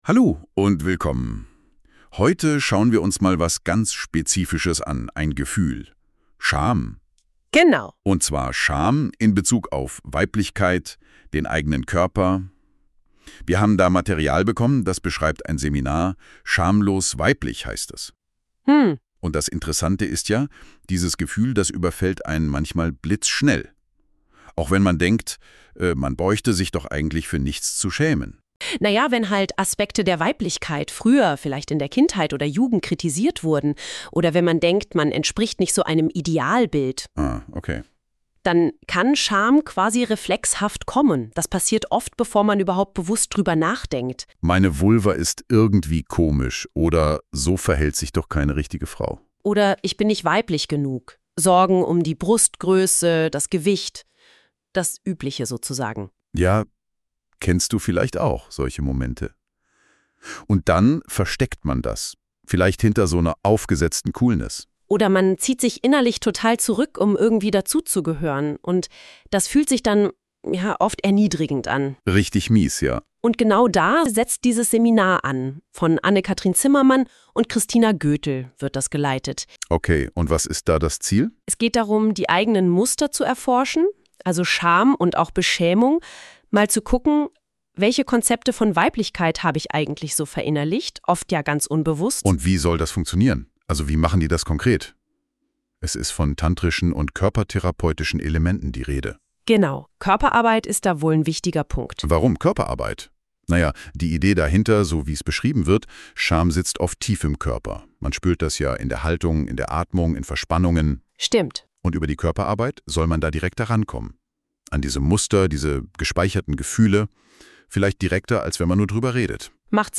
Gespräch über das Seminar „Schamlos weiblich“ – 3. bis 5. Oktober 2025, Berlin
Dieser Podcast wurde mit Ki erstellt.